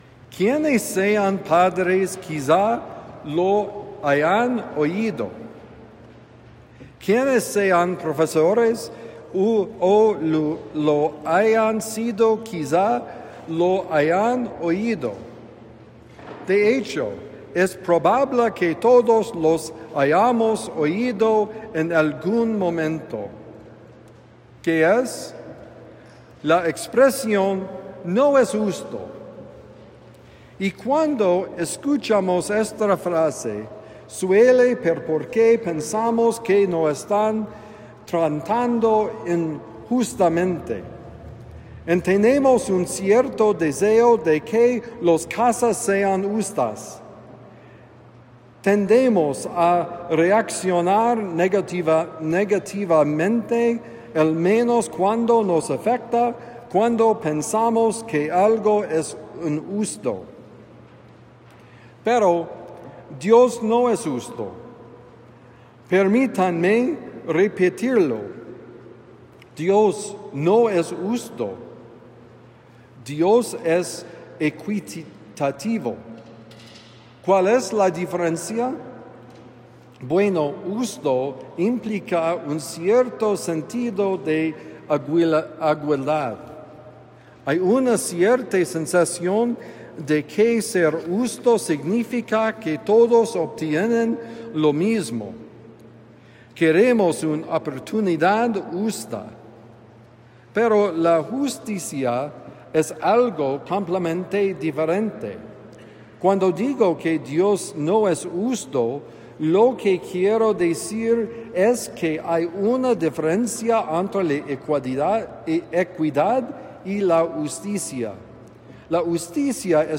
¿Qué es la justicia?: Homilía para el domingo 27 de julio de 2025 – The Friar